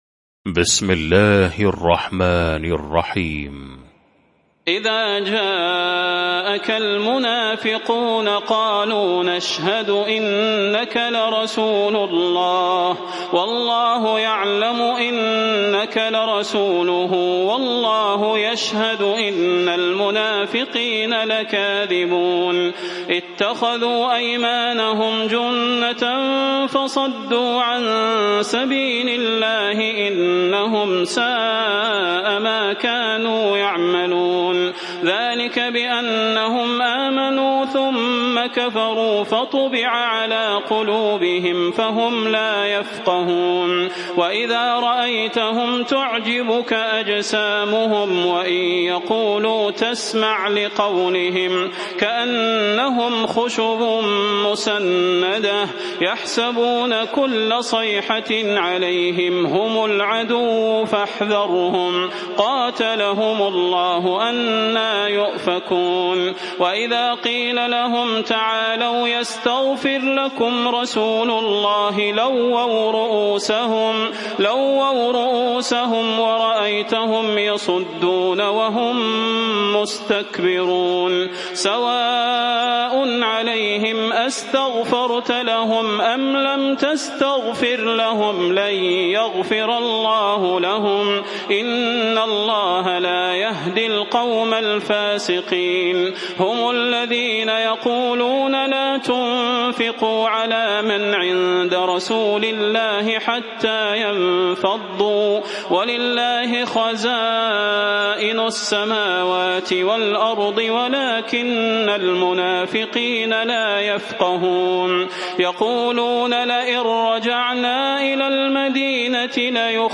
المكان: المسجد النبوي الشيخ: فضيلة الشيخ د. صلاح بن محمد البدير فضيلة الشيخ د. صلاح بن محمد البدير المنافقون